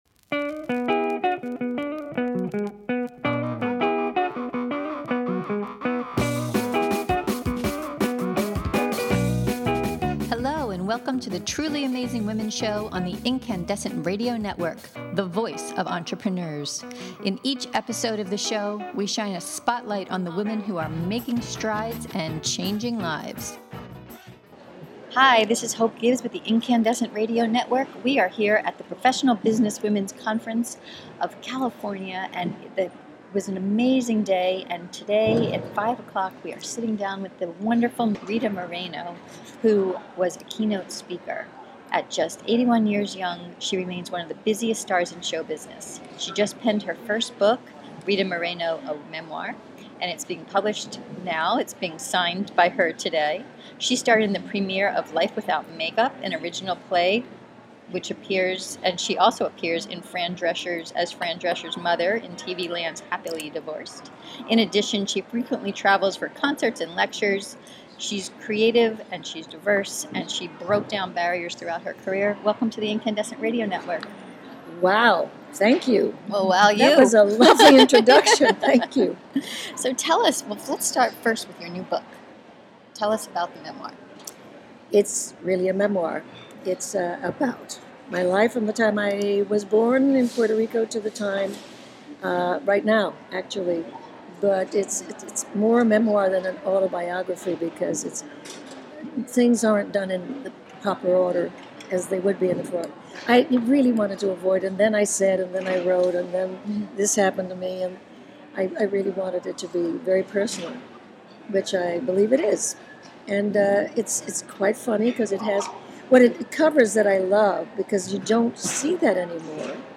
In our 30-minute podcast interview: We discussed her current impressive appearances on the stage and TV screen.